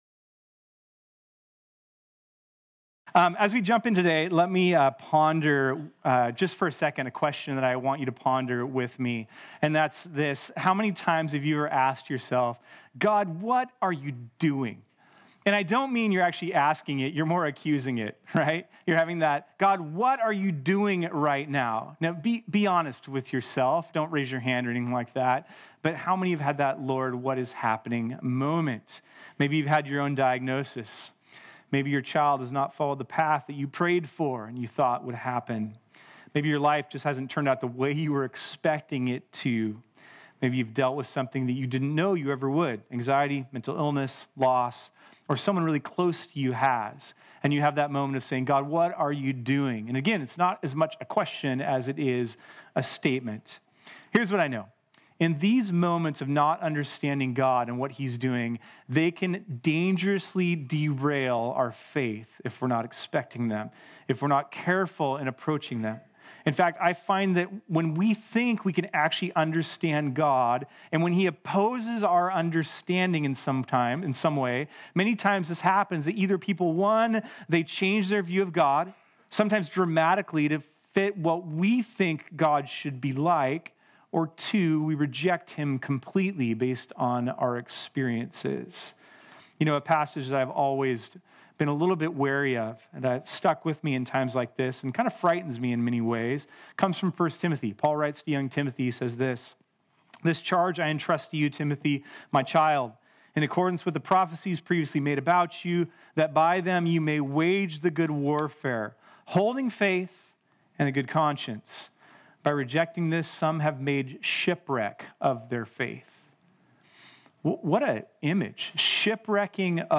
This sermon was originally preached on Sunday, June 2, 2019.